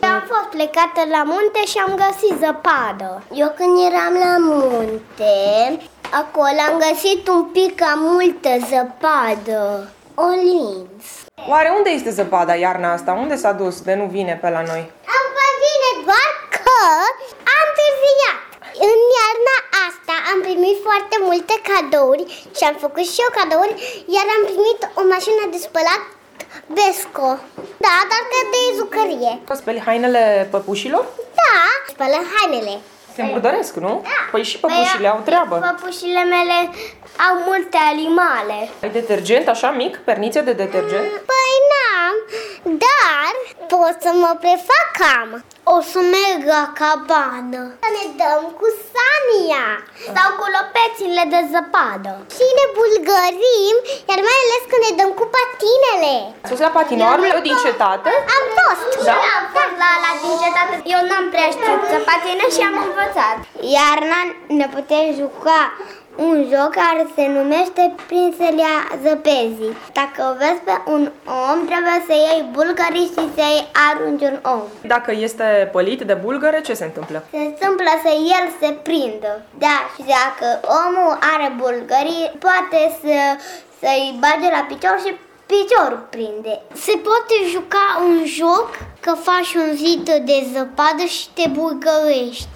Copiii ne explică jocul „prinselea zăpezii”: